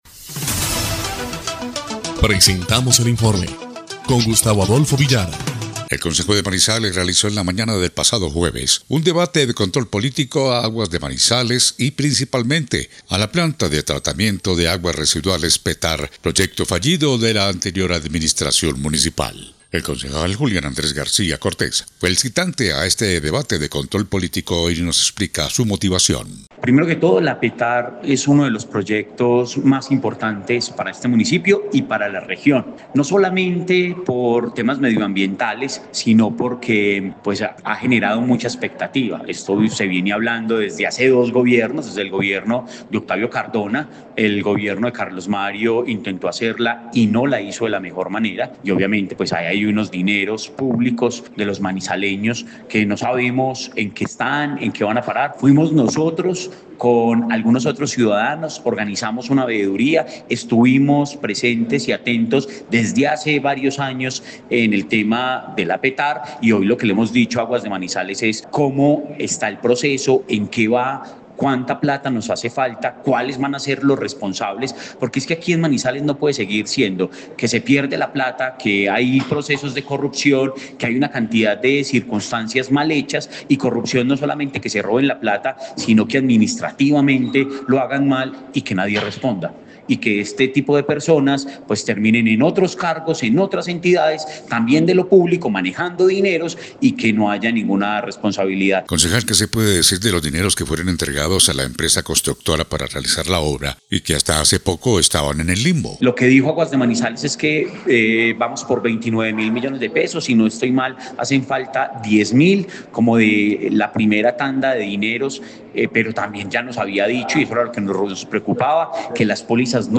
EL INFORME 1° Clip de Noticias del 4 de abril de 2025